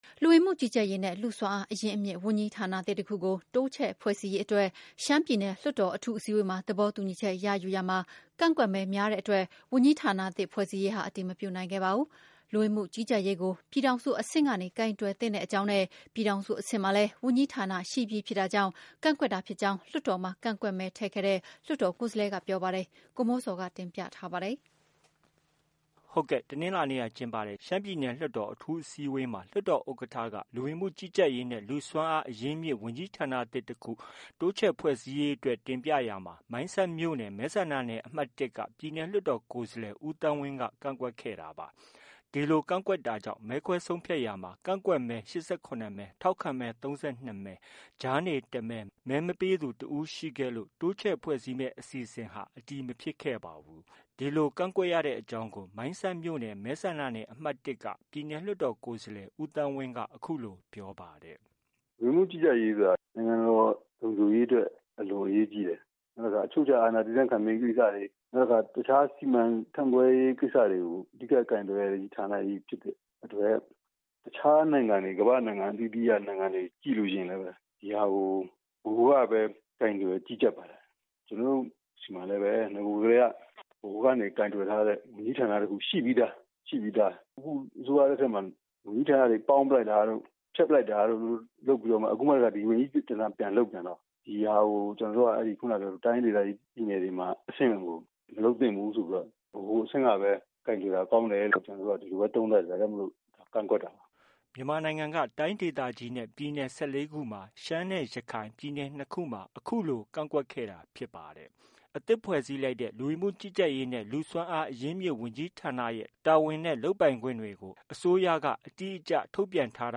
by ဗွီအိုအေသတင်းဌာန